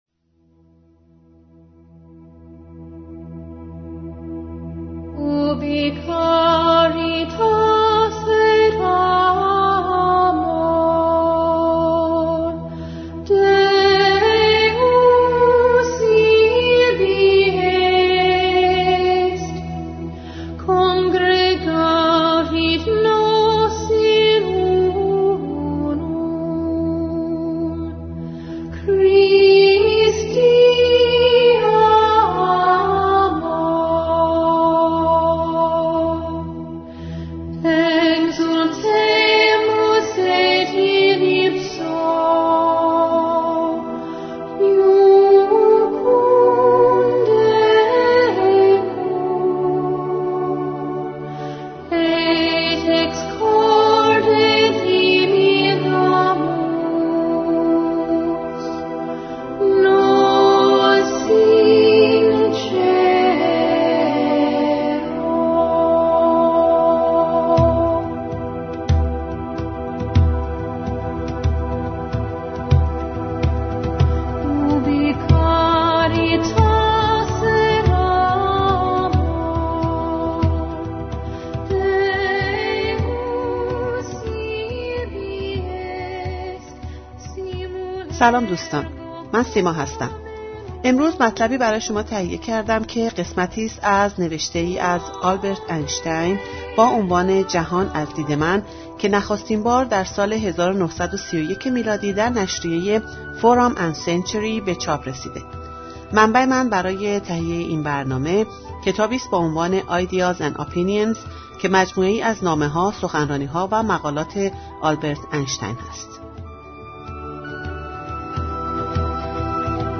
جهان از دید من (اینشتن) برگرفته شده از سایت کتابهای صوتی بلاگفا